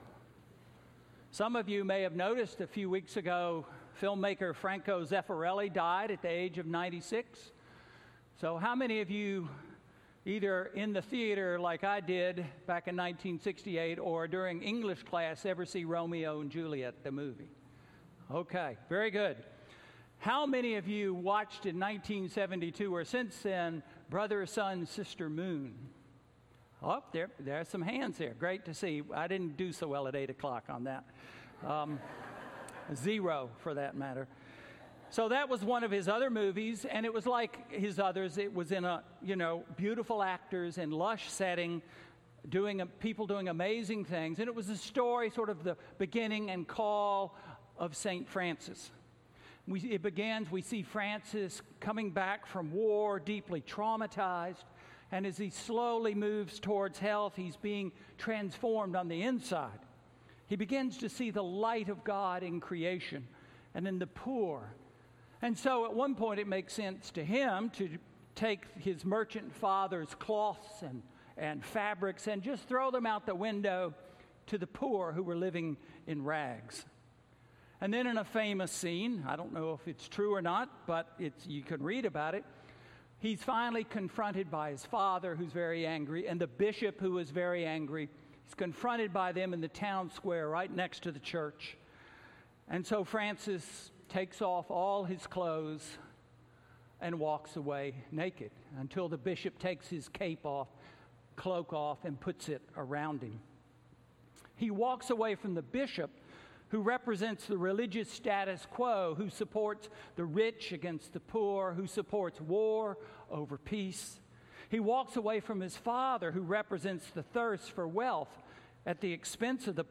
Sermon: I am following Jesus?